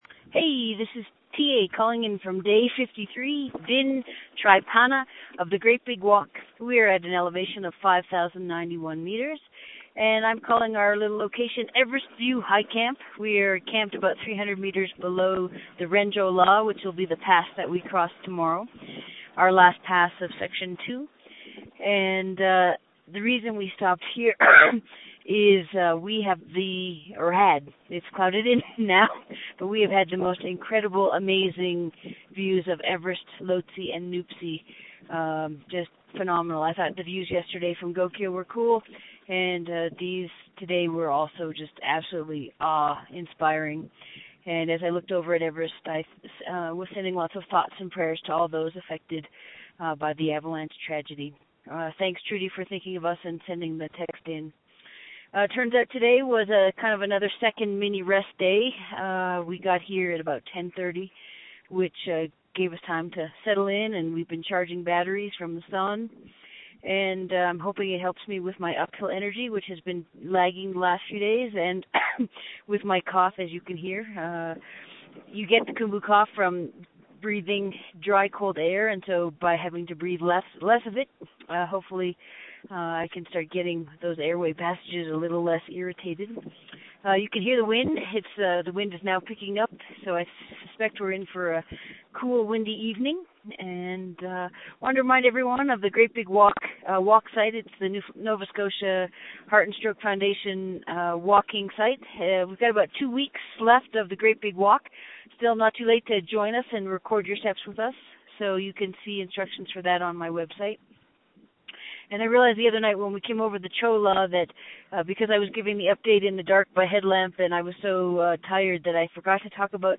Great Big Walk acknowledges the support of the Memorial University of Newfoundland Quick Start Fund for Public Engagement in making these updates from the field possible.